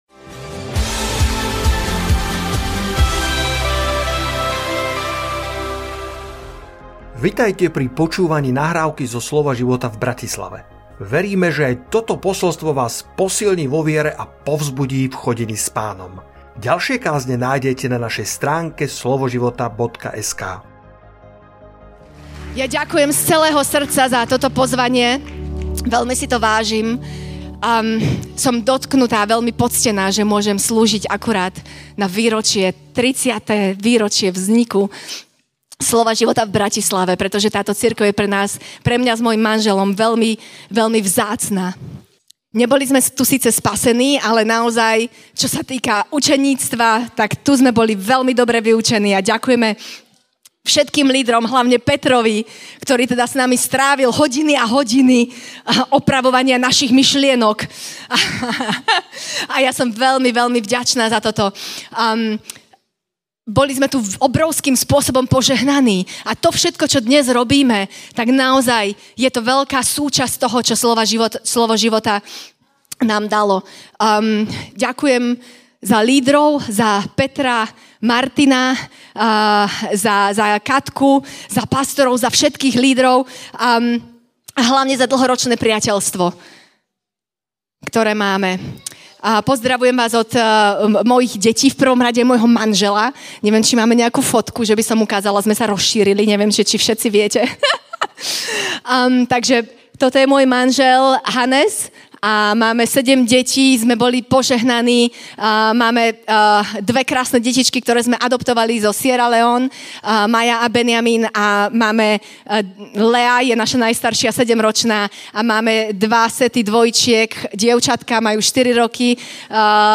Konferencia viery